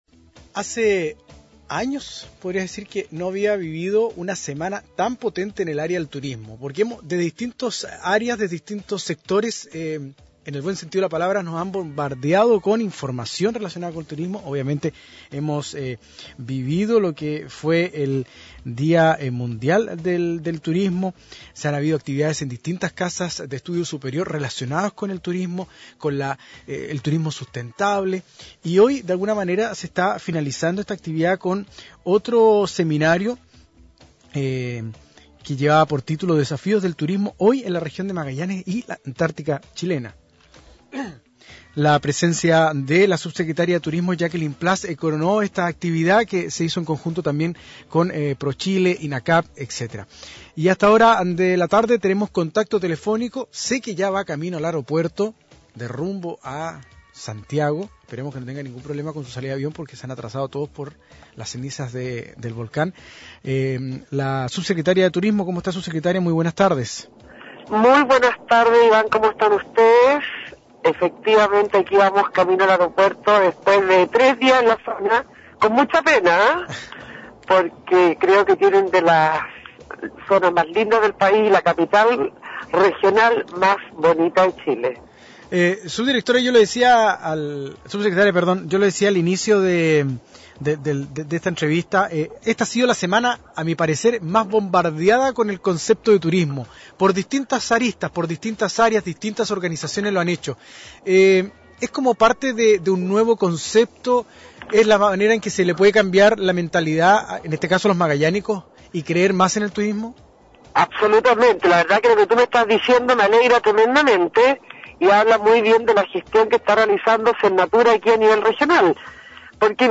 Entrevistas de Pingüino Radio
Jacqueline Plass, Subsecretaria de Turismo